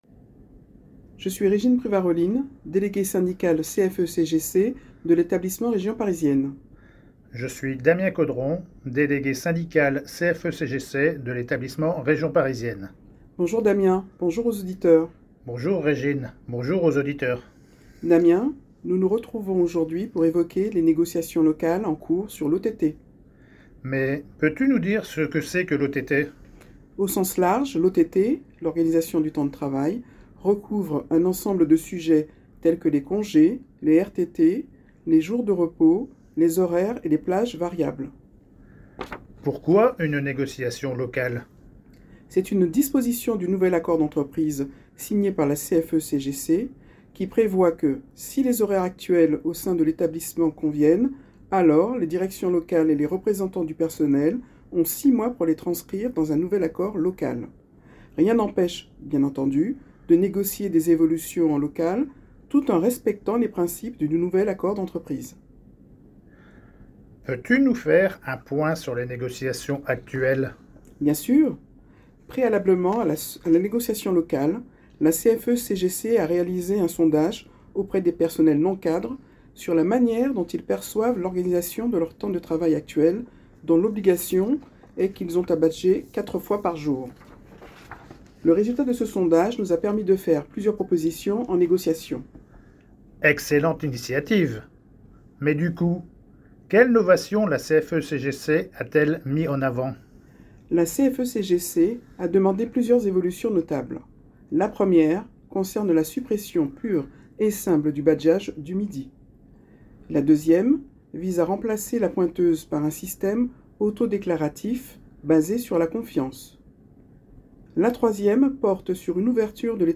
Vos négociateurs CFE-CGC vous présentent en moins de 3 minutes les enjeux ainsi que nos propositions.